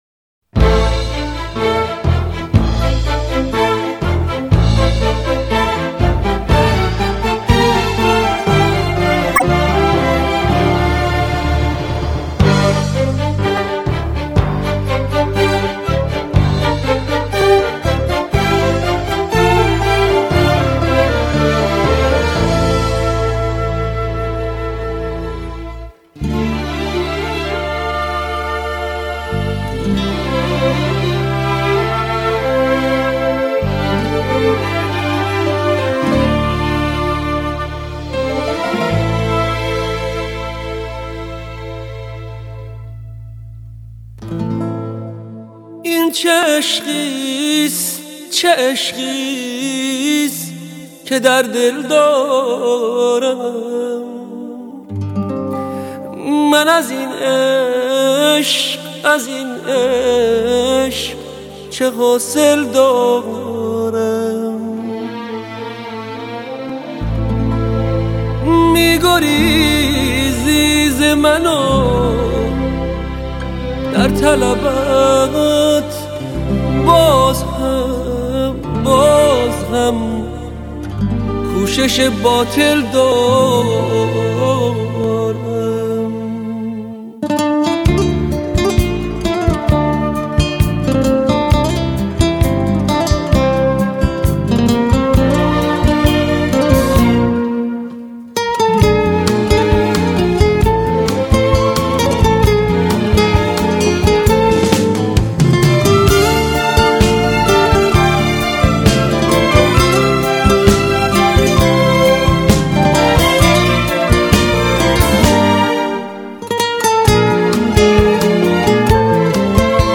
صدای مخملین